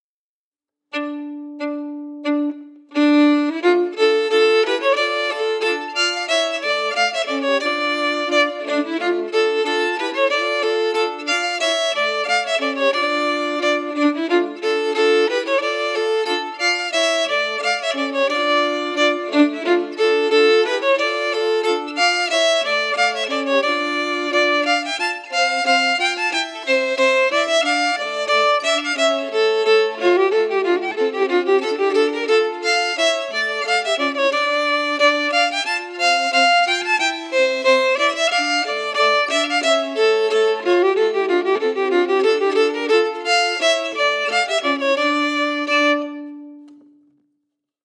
Key: Dm*
Melody emphasis
R:Reel (8x40) ABABB
Region: Scotland